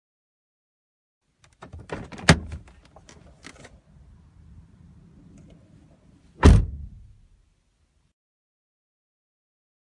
开启关闭汽车卡车车门
描述：打开关闭汽车卡车车门